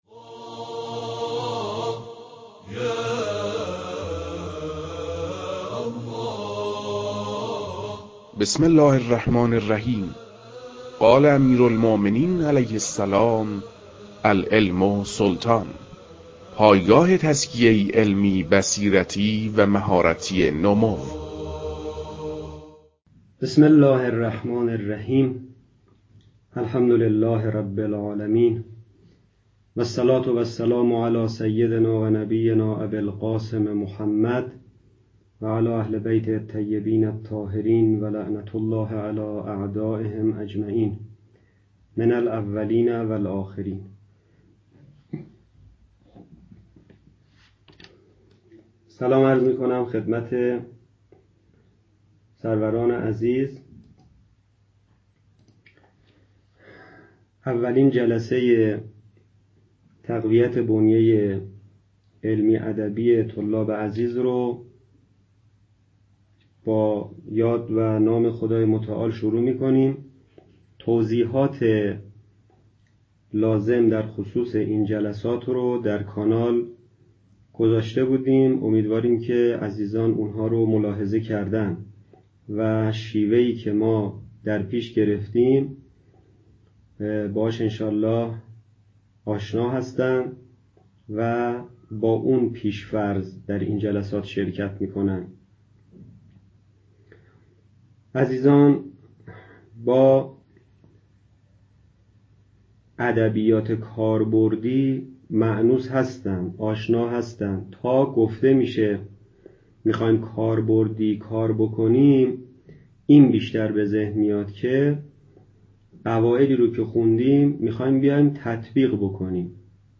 در این بخش، صوت و یا فیلم سخنرانیهای علمی، گفتگوهای علمی، نشست علمی، میزگردها و مناظرات علمی مرتبط با دروس حوزوی درج می‌گردد.